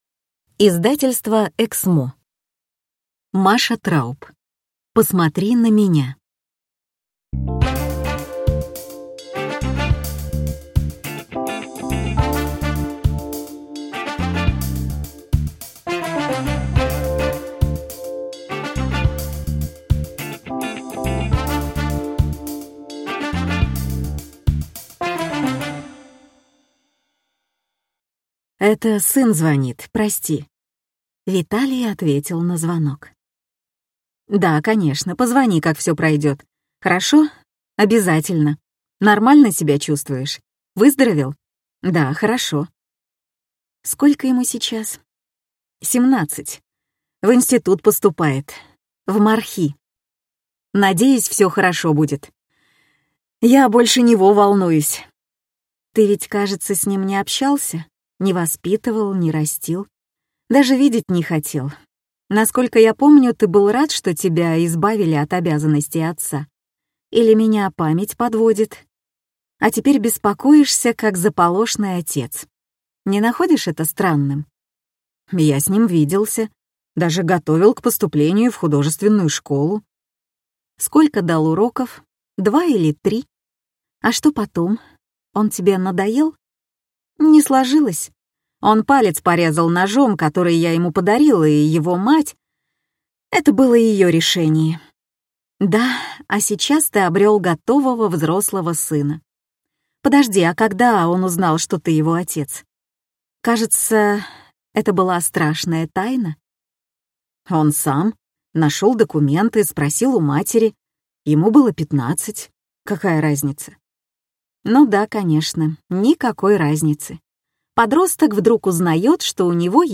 Аудиокнига Посмотри на меня | Библиотека аудиокниг